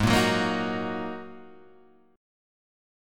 G# 7th Suspended 2nd